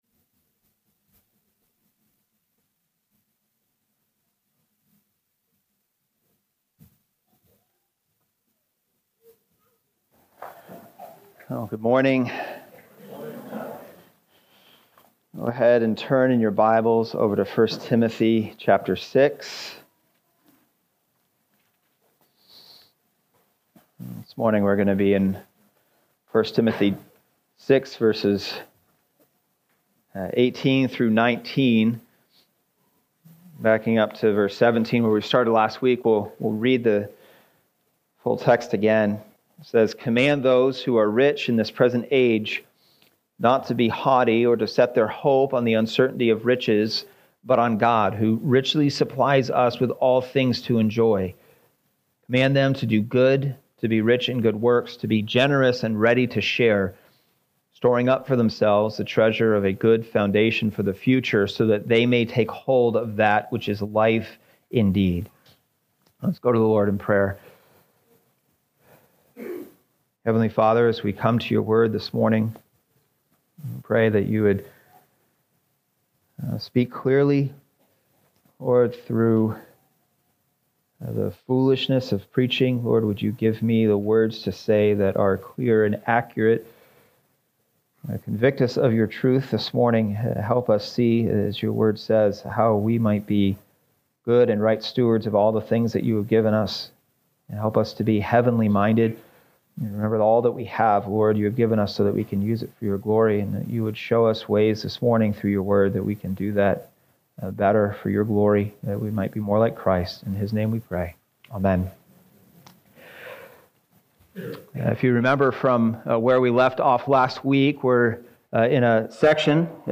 Latest Sermons